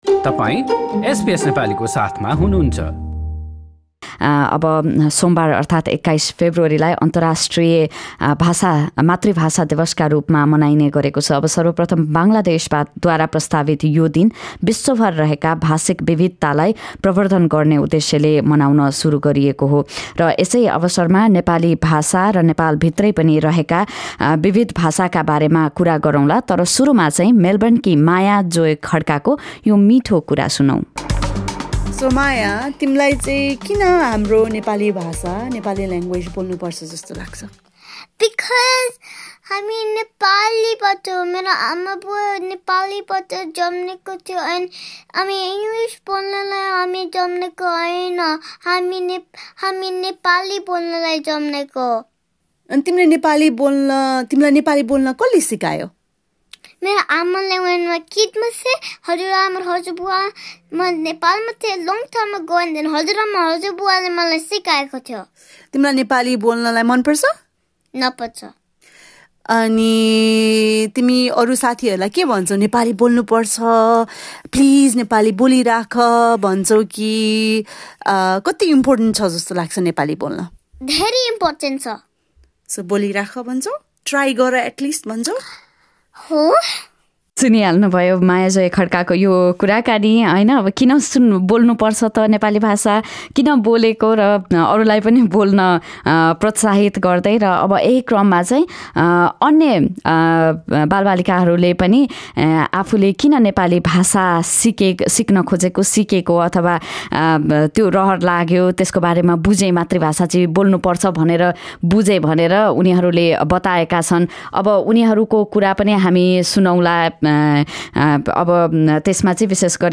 फेब्रुअरी २१, अन्तराष्ट्रिय मातृभाषा दिवसको अवसर पारेर हामीले अस्ट्रेलियाका नेपाली भाषी अभिभावक र बालबालिकासँग गरेको कुराकानी।